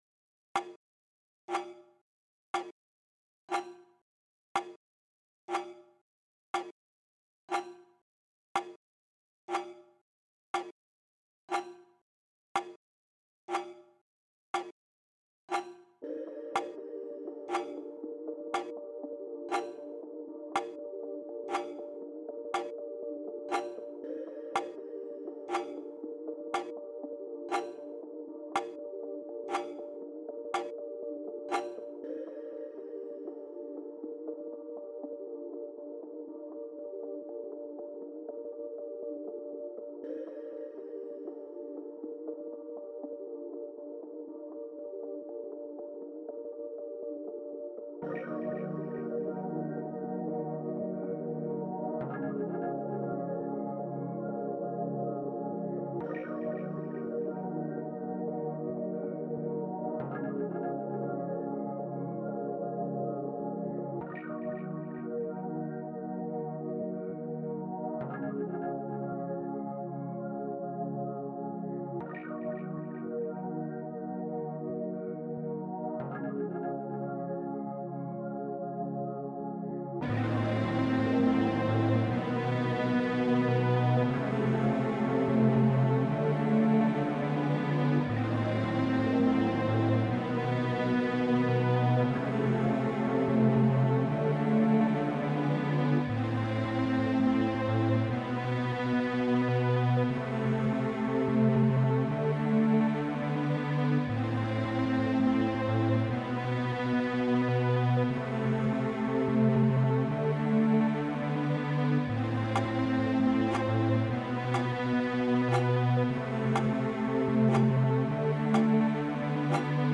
mp3,3376k] Поп